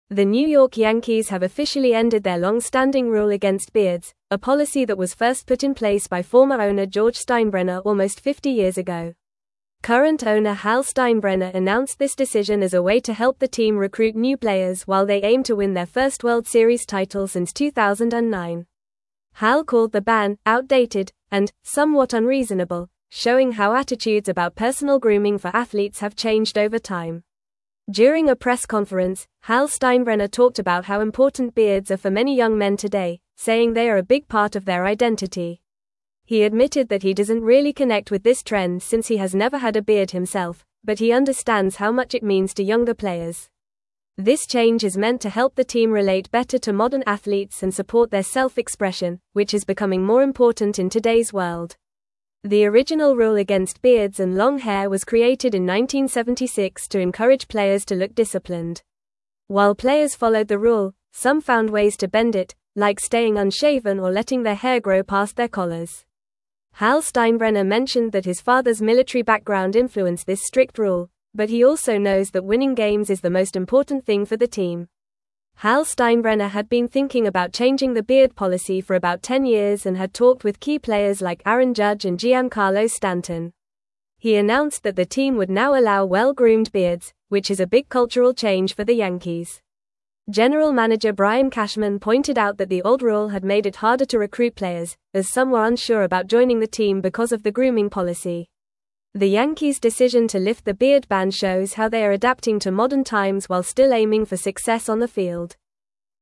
Fast
English-Newsroom-Upper-Intermediate-FAST-Reading-Yankees-Lift-Longstanding-Beard-Ban-for-Players.mp3